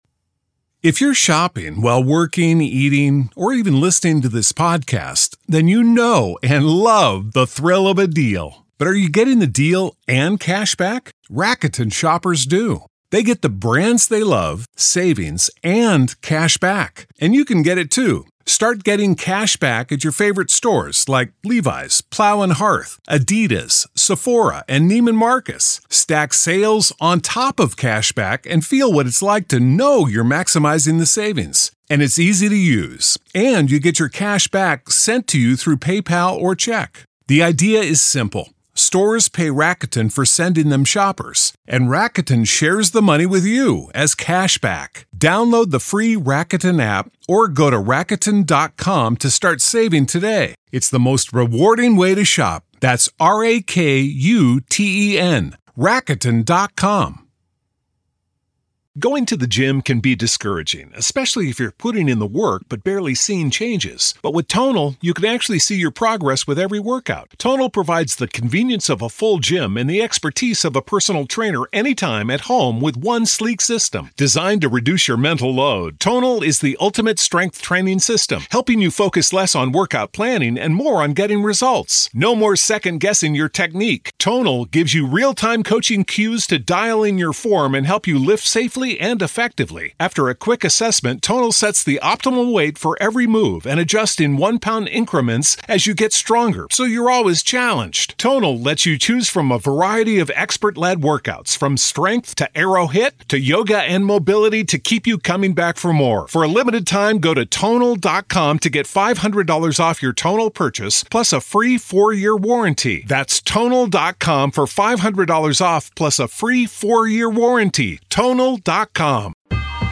Judge Justin Beresky issued the decision during a hearing, keeping a Rule 11 competency evaluation in place despite objections from her defense...